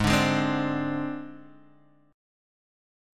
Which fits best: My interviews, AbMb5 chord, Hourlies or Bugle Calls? AbMb5 chord